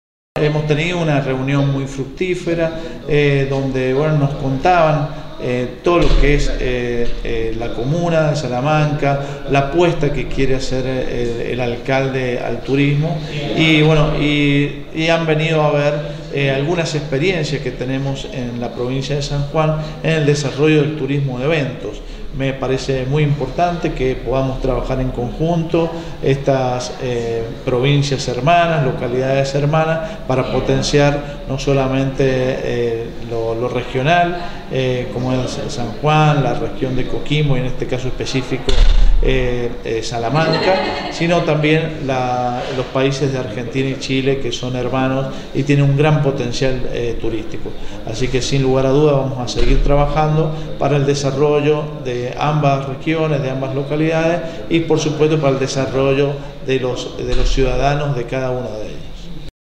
Roberto Juárez, Secretario de Turismo de la Provincia de San Juan, valoró la reunión de trabajo sostenida con el alcalde Rojas Escudero y la comitiva de prestadores de servicios turísticos y empresarios de la minería salamanquinos: